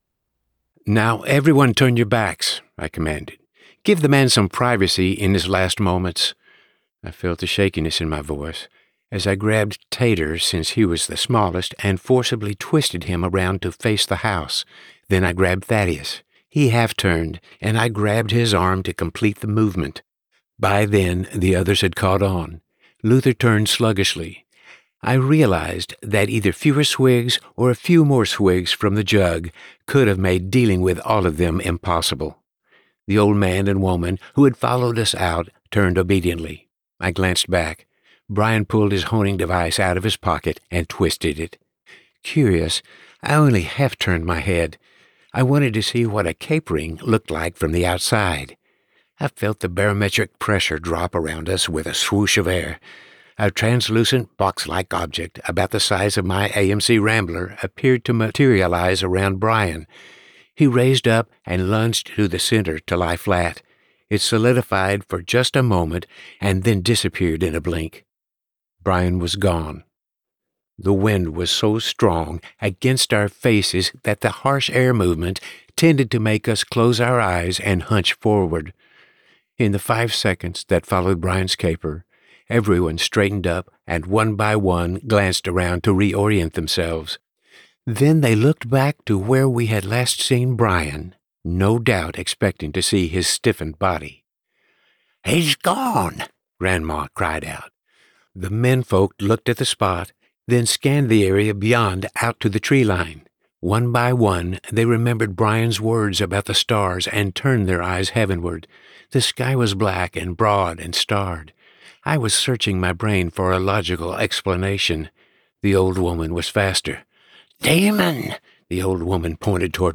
The-Long-caper-by-Paul-Charles-Reed_science-fiction_sample.mp3